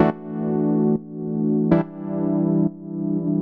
Index of /musicradar/sidechained-samples/140bpm
GnS_Pad-alesis1:2_140-E.wav